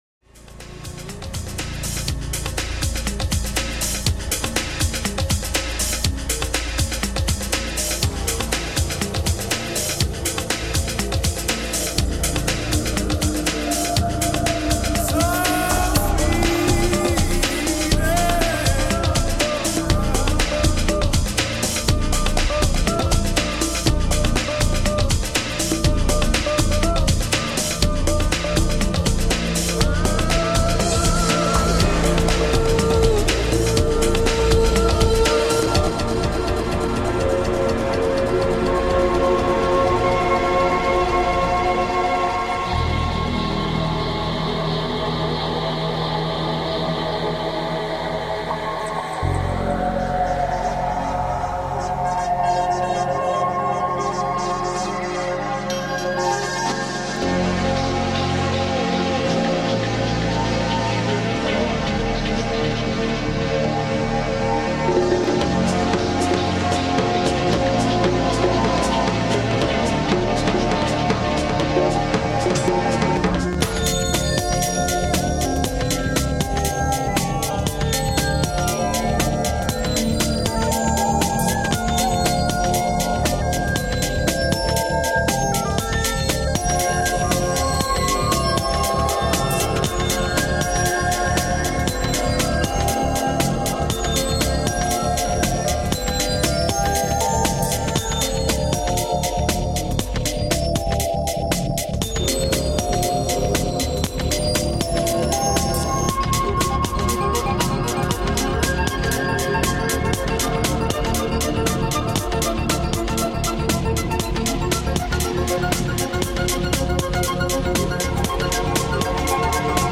Energetic and organic clubhouse.
Tagged as: Electronica, Other